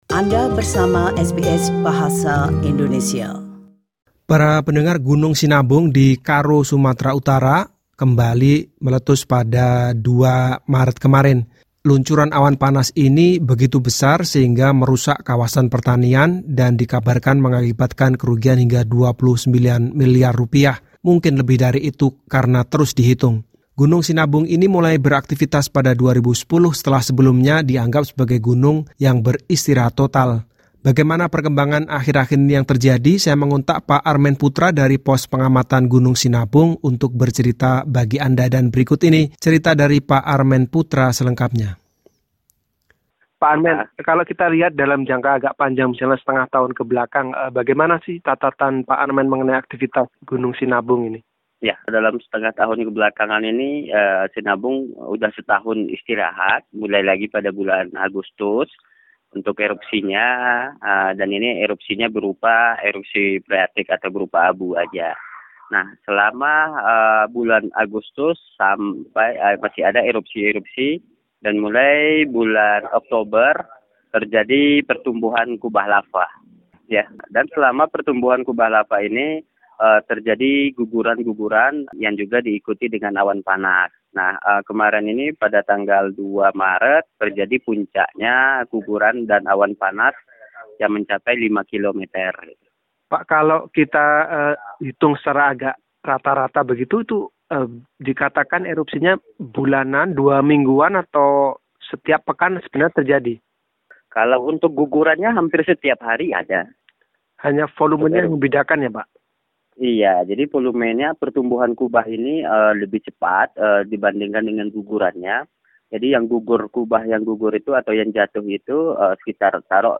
berbicara tentang aktivitas gunung berapi dalam wawancaranya